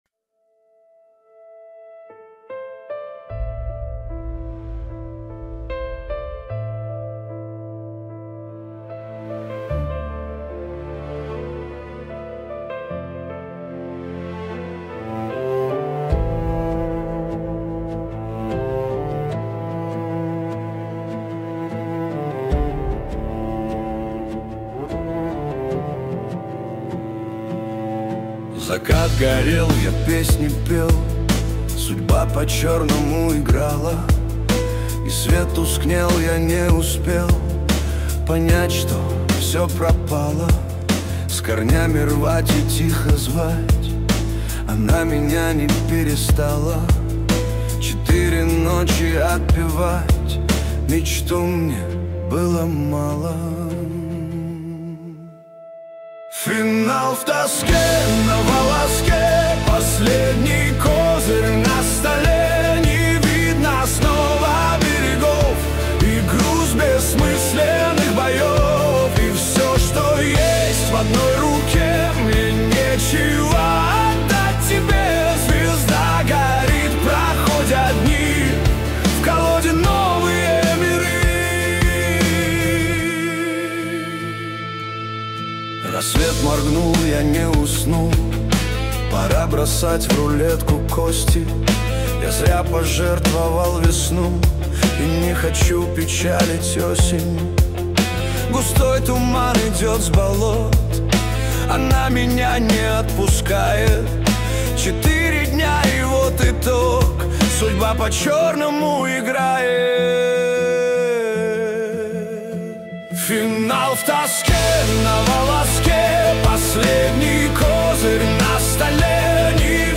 Качество: 192 kbps, stereo